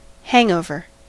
Ääntäminen
US : IPA : [ˈhæŋ.ˌoʊ.vɚ]